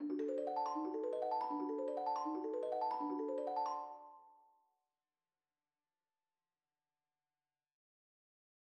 사탕 요정의 첼레스타에서 연주되는 캐스케이딩 아르페지오.